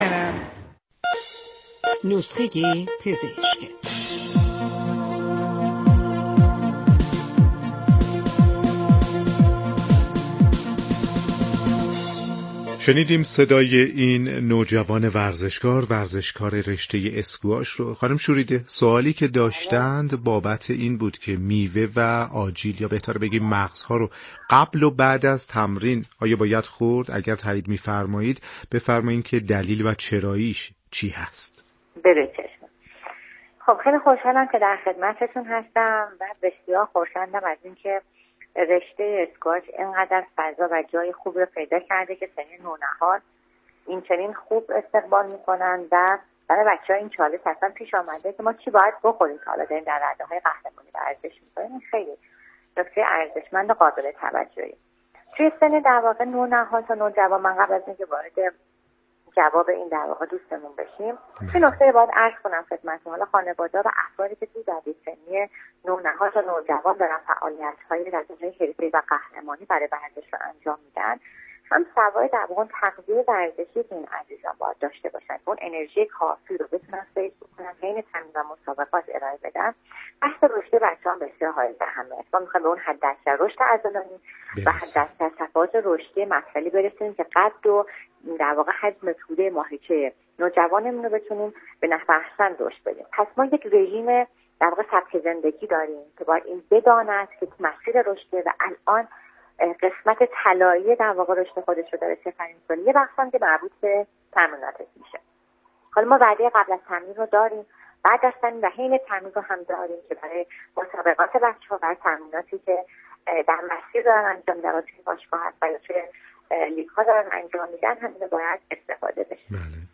/پزشکی ورزشی و رادیو ورزش/